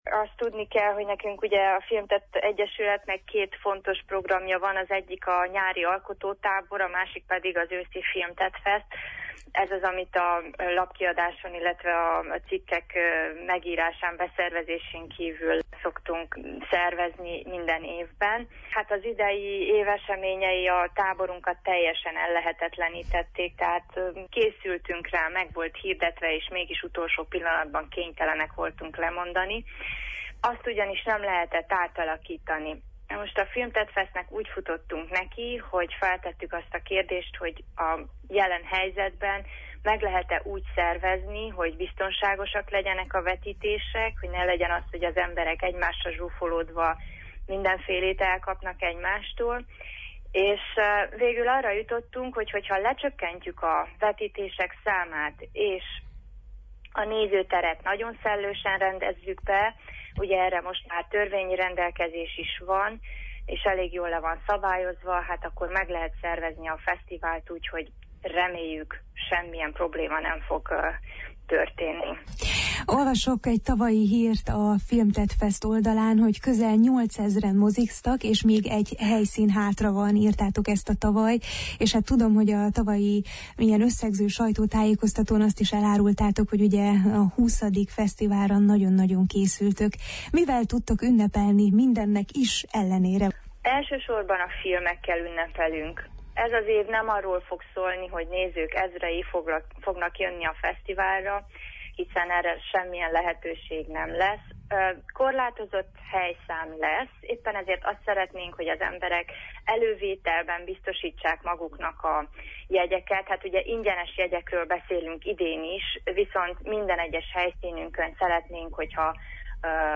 Filmtettfeszt egyik szervezőjével